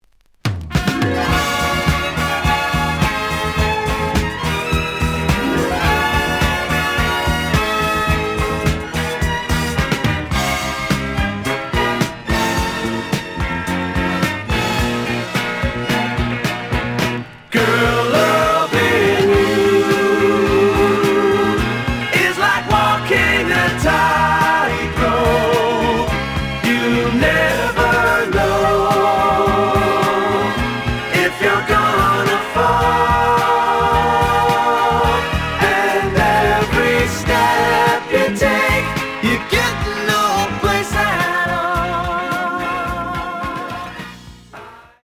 The audio sample is recorded from the actual item.
●Genre: Rock / Pop
B side plays good.